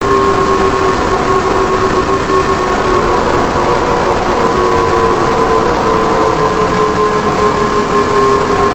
Шумы сигнализирующие о приближении Амбуш
Амбуш_шум_2.mp3.mp3